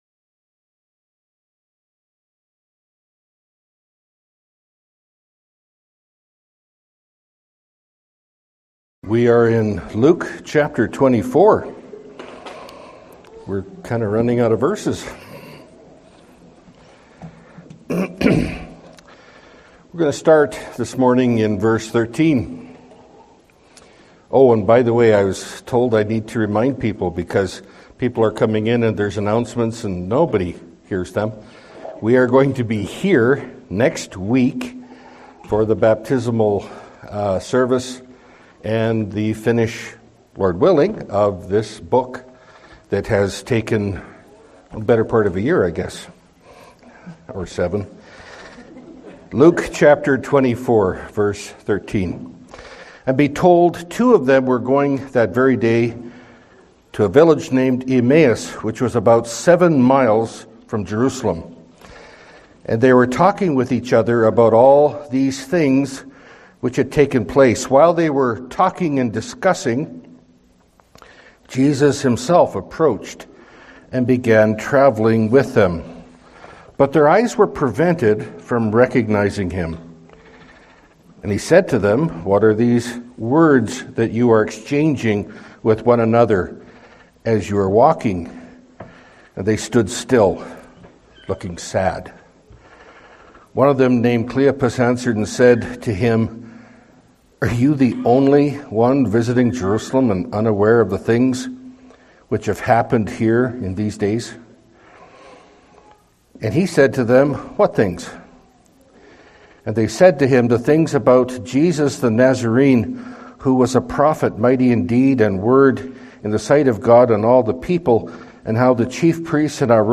Pulpit Sermons Key Passage